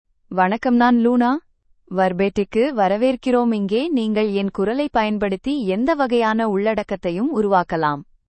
Luna — Female Tamil AI voice
Luna is a female AI voice for Tamil (India).
Voice sample
Listen to Luna's female Tamil voice.
Female
Luna delivers clear pronunciation with authentic India Tamil intonation, making your content sound professionally produced.